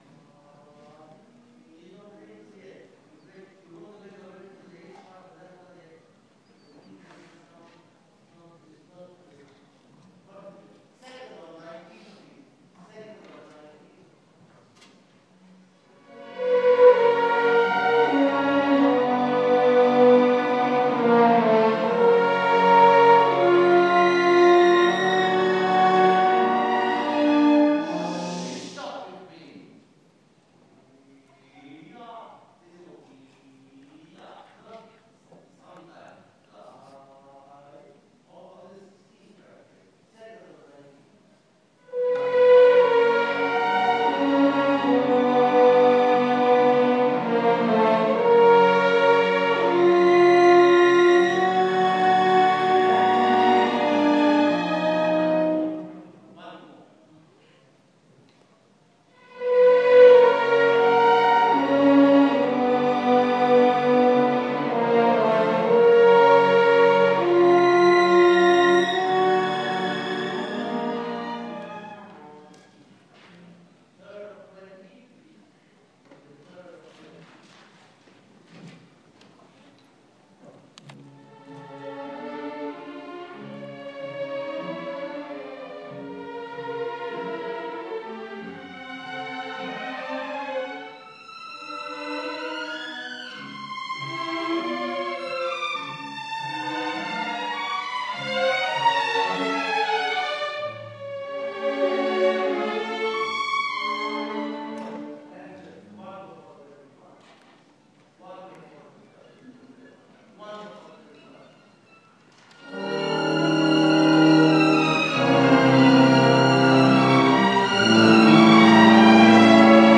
Ensayo en La Scala